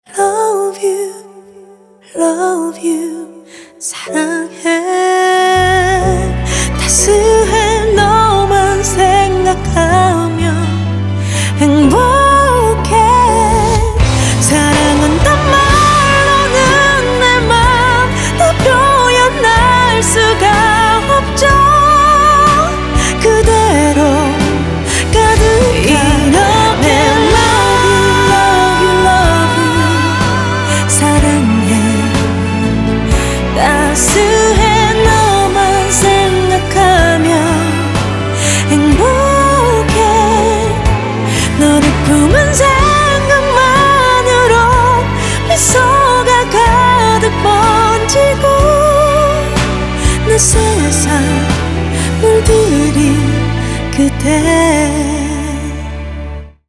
• Качество: 320, Stereo
спокойные
чувственные
романтичные
красивый женский голос
ballads
K-Pop